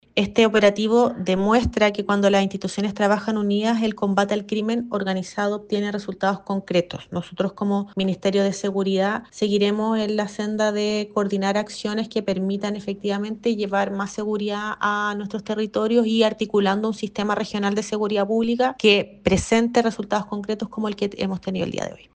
Por su parte, la seremi de Seguridad Pública, Alejandra Romero, destacó que el operativo se haya realizado en coordinación con diferentes instituciones, señalando que esta es una demostración concreta que se puede combatir al crimen organizado de manera efectiva.